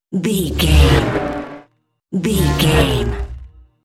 Sci fi vehicle pass by fast
Sound Effects
futuristic
pass by
vehicle